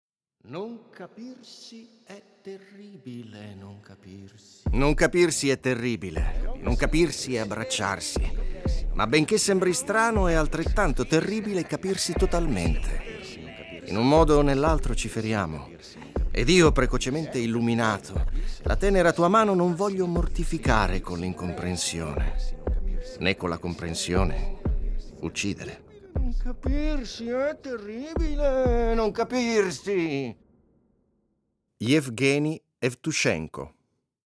Abbiamo immaginato un contenitore dove si possano ascoltare delle prime letture poetiche.